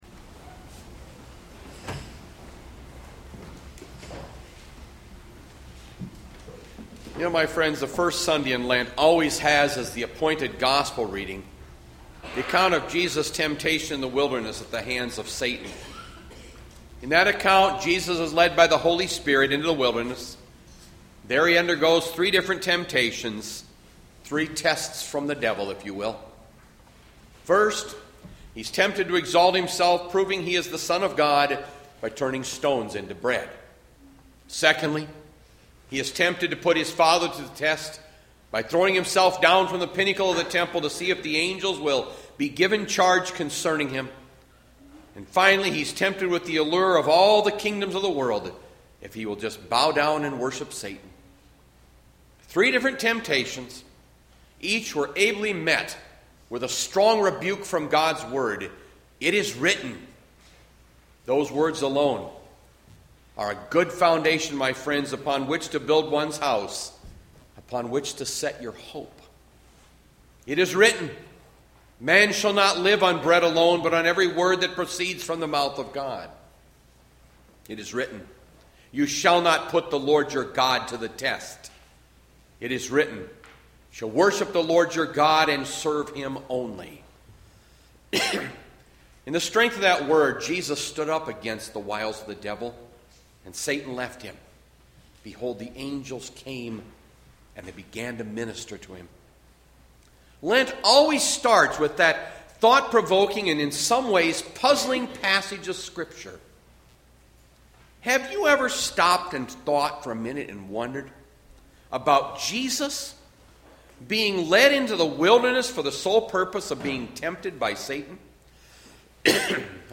Bethlehem Lutheran Church, Mason City, Iowa - Sermon Archive Mar 1, 2020